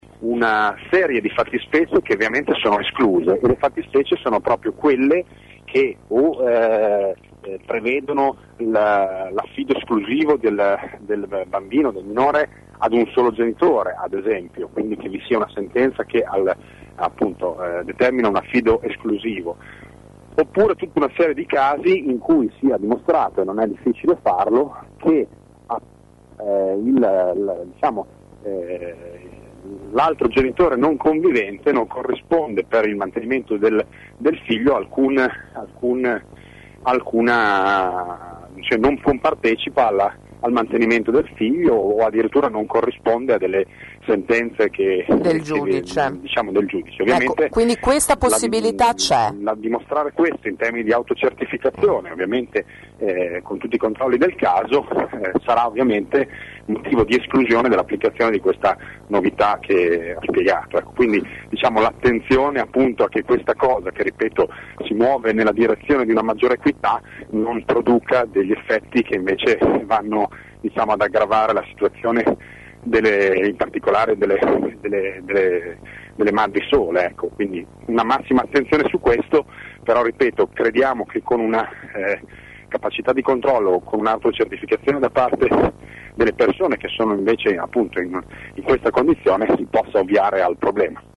Lo spiega l’assessore Luca Rizzo Nervo il giorno dopo il via libera della giunta al nuovo Isee che prevede che verranno calcolati i redditi di entrambi i genitori indipendentemente da dove vivono.